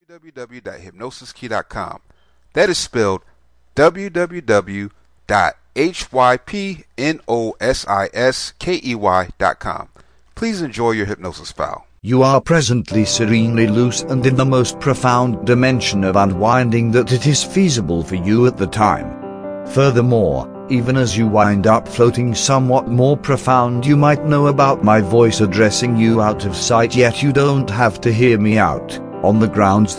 Welcome to Settling In A Country Self Hypnosis Mp3, this is a powerful hypnosis script that helps you settle into a new country if you move.